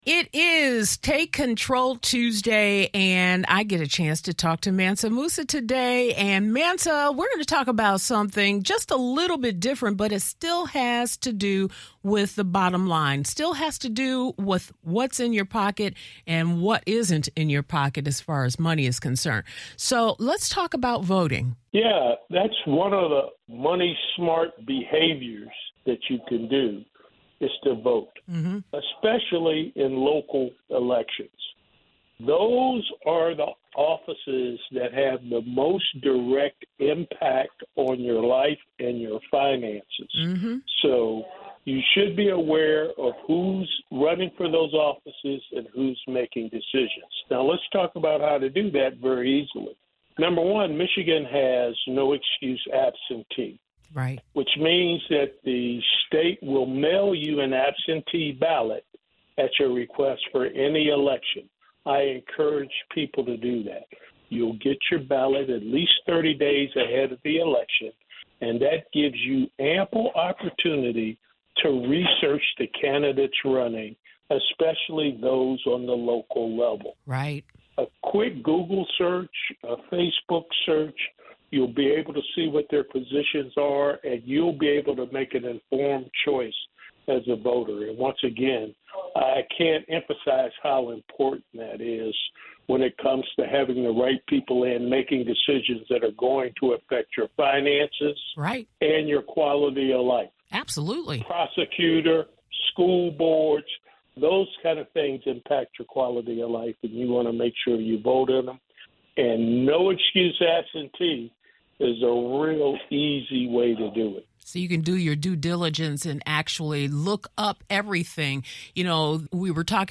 Local elections determine the day-to-day operations of your city, county, and state. Listen to our conversation below.